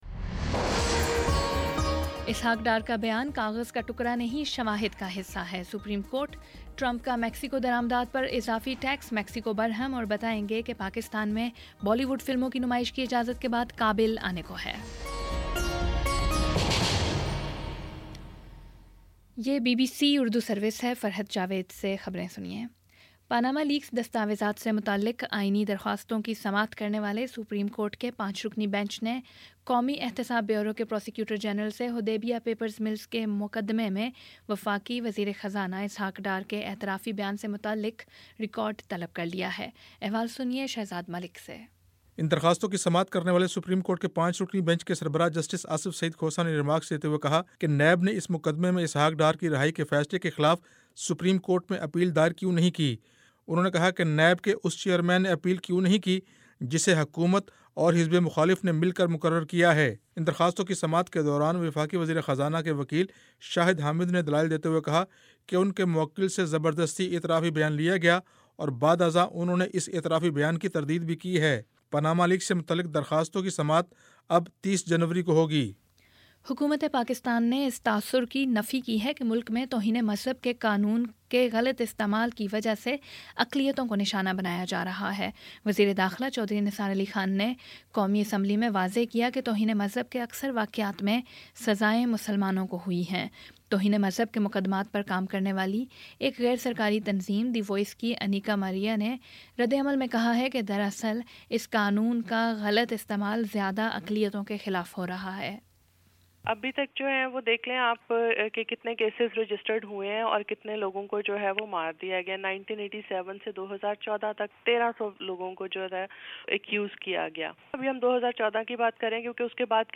جنوری 27 : شام سات بجے کا نیوز بُلیٹن
دس منٹ کا نیوز بُلیٹن روزانہ پاکستانی وقت کے مطابق شام 5 بجے، 6 بجے اور پھر 7 بجے۔